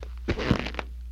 Leather Squeak Twist